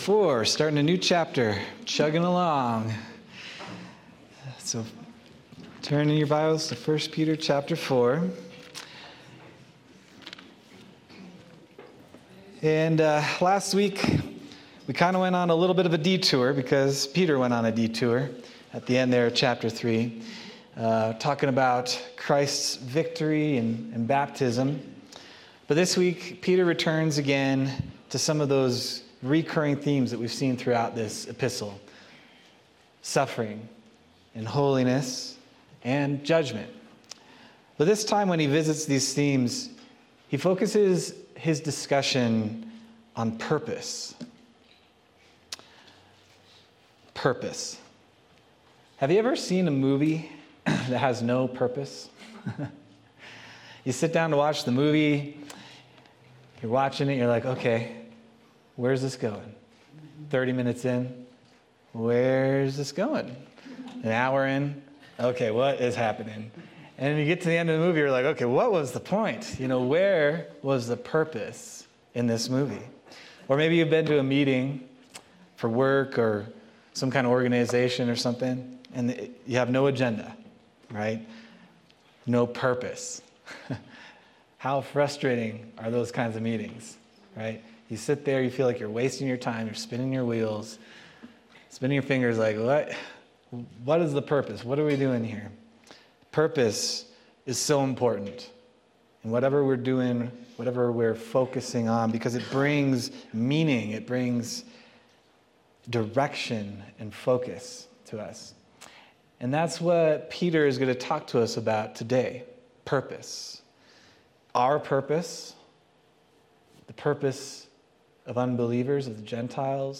June 8th, 2025 Sermon